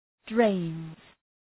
Προφορά
{dreınz}